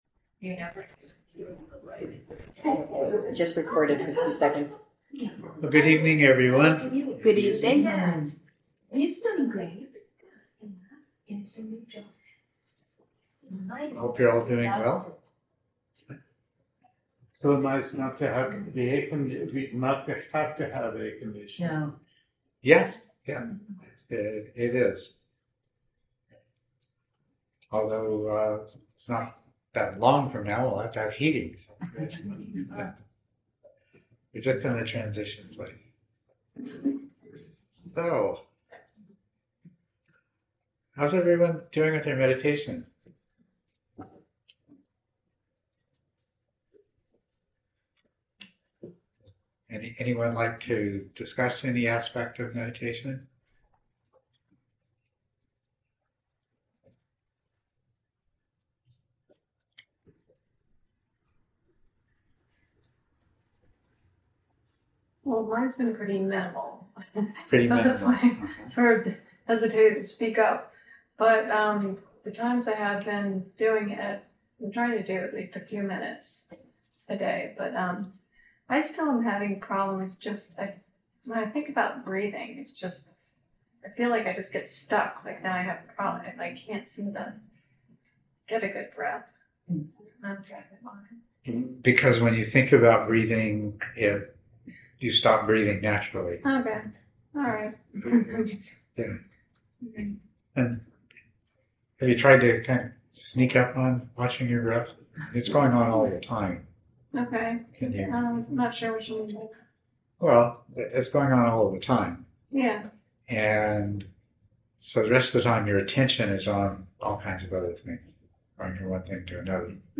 Edit talk Download audio (mp3) Download original audio Listen to original audio * Audio files are processed to reduce background noise, and provide (much) better compression.